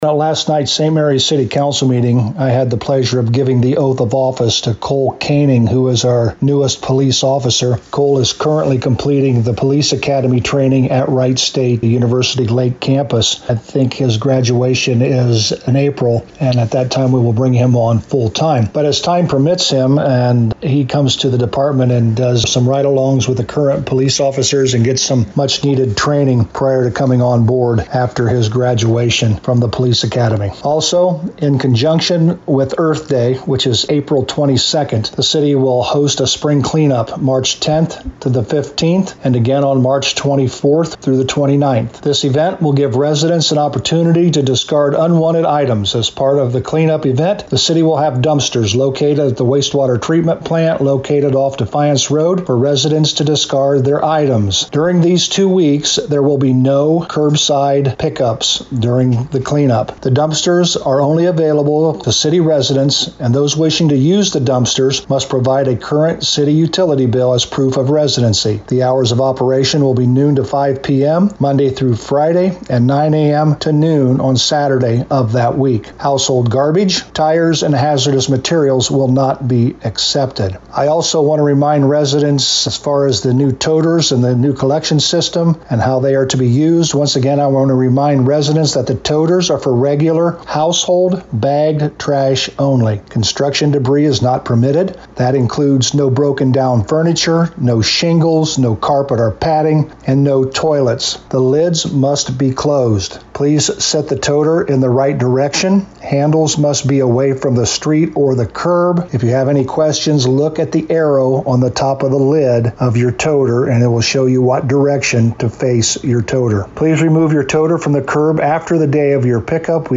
To hear the summary with St Marys Mayor Joe Hurlburt: